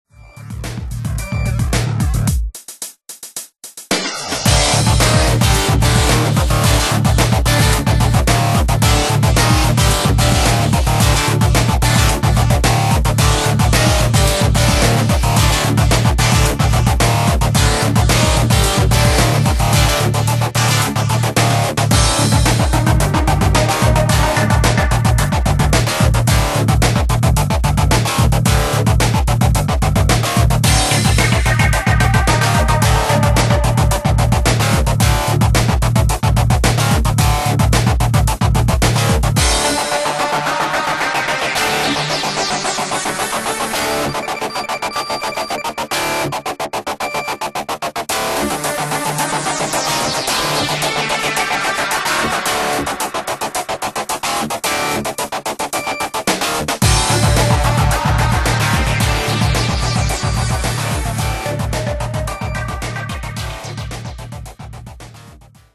So, it sounds much more professional now Smile
Always loved this songs - but the new version sounds too glassy and blocky for me.